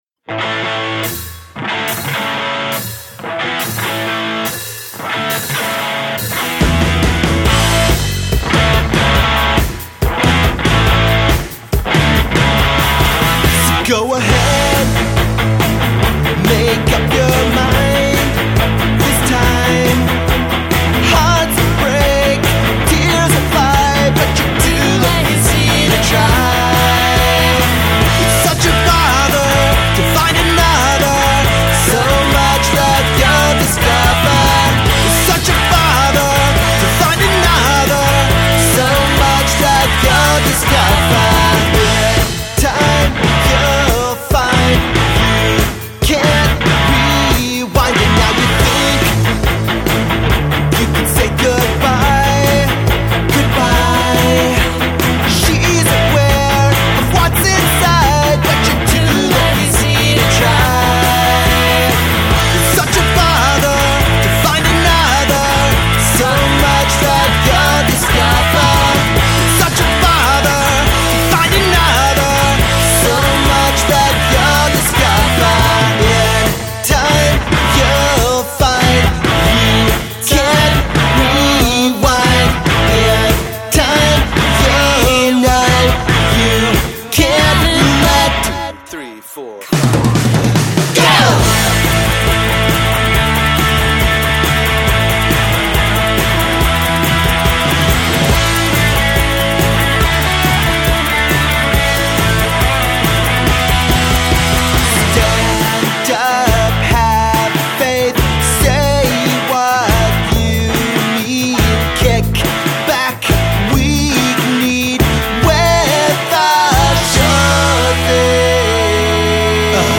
described as a power-punk fist punch trio